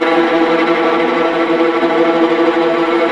Index of /90_sSampleCDs/Roland LCDP08 Symphony Orchestra/STR_Vas Bow FX/STR_Vas Tremolo